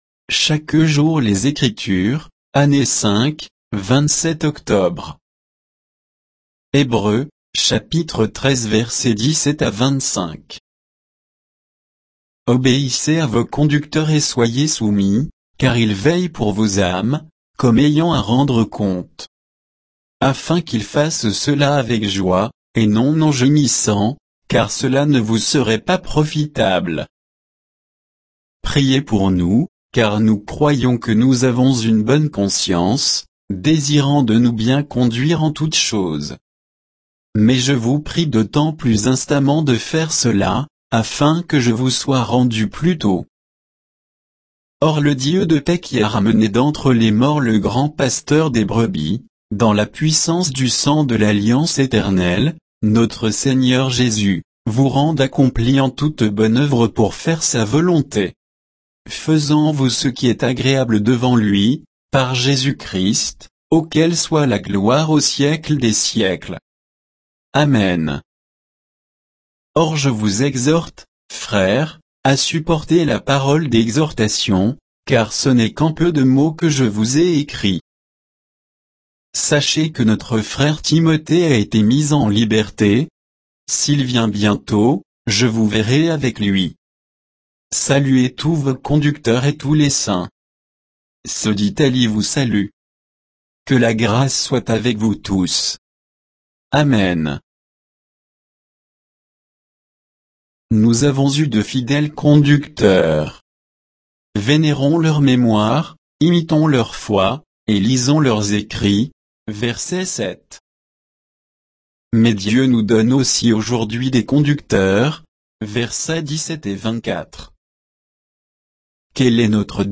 Méditation quoditienne de Chaque jour les Écritures sur Hébreux 13, 17 à 25